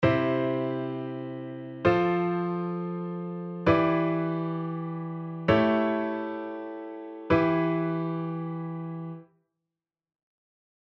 ↓は、転調したC⇒F⇒Em7⇒A7⇒Dです。